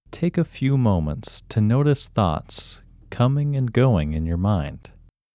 WHOLENESS English Male 2
WHOLENESS-English-Male-2.mp3